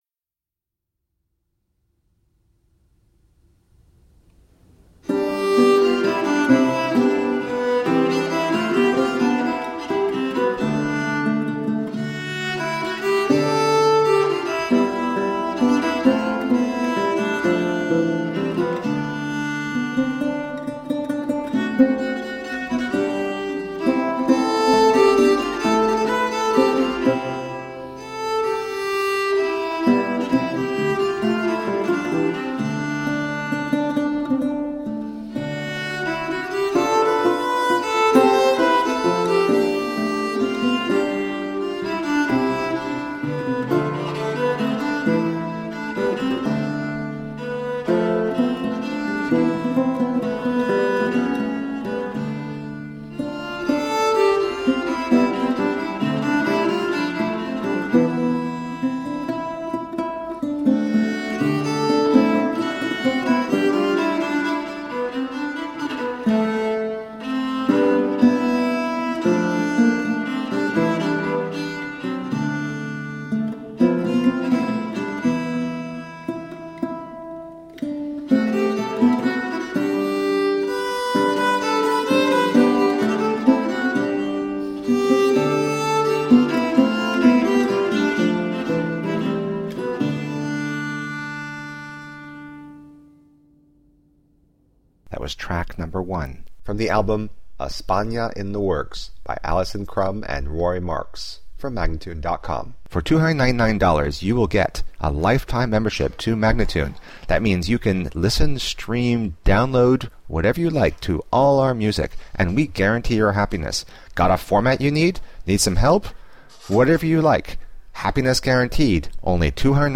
Classical, Renaissance, Instrumental
Lute, Viola da Gamba